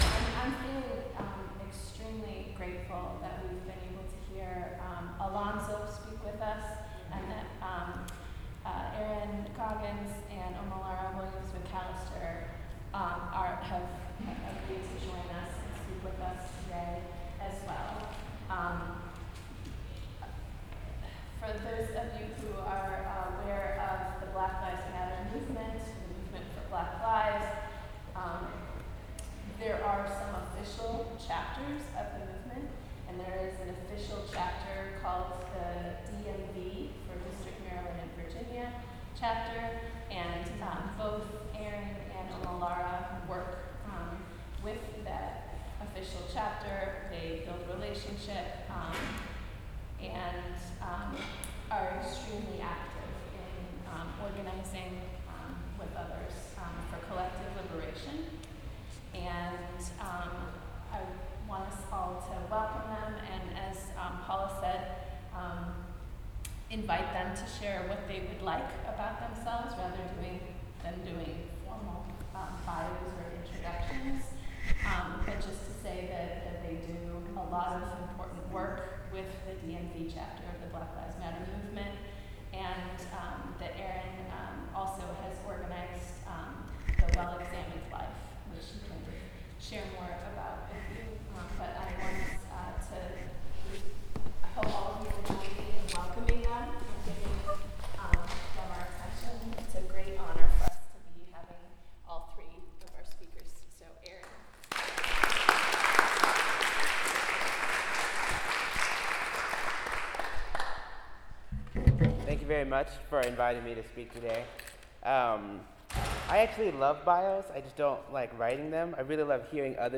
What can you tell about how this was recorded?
So here is the raw audio from that talk and Q&A.